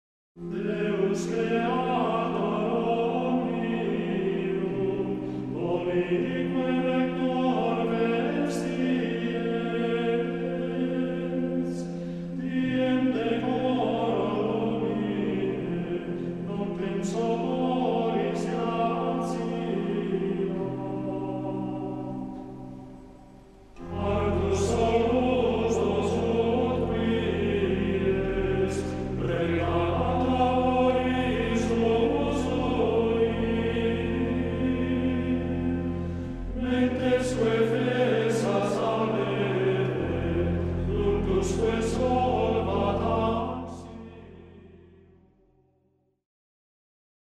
Audio - Cors. Vespres a Montserrat Audio - Grup Psalterium Esg. d’Ureña
GregoriaMonjosdeMontserrat.mp3